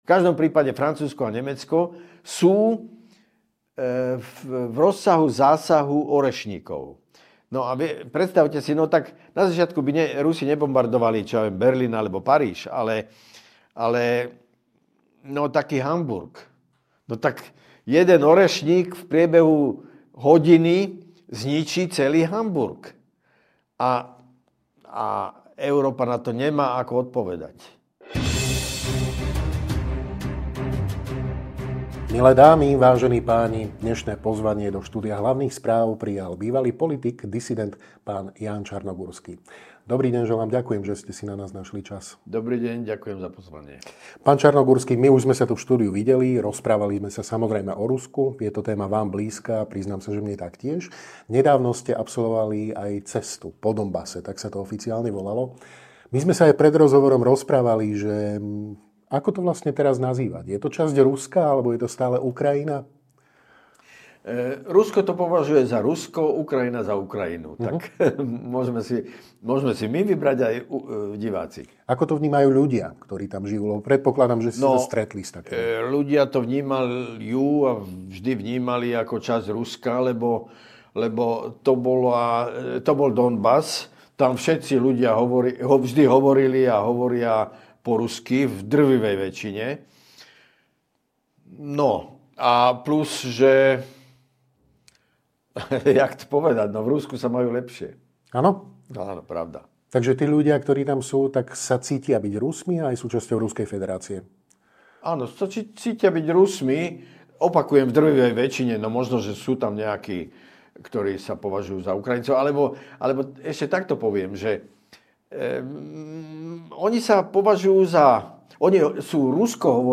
Nielen o Donbase a náladách medzi miestnymi, ale aj o tom, prečo západ nemá záujem o mier, o ruskej trpezlivosti a o tom, prečo by vojna s Ruskom znamenala koniec Európy budete počuť vo videorozhovore s bývalým politikom a disidentom, JUDr. Jánom Čarnogurským.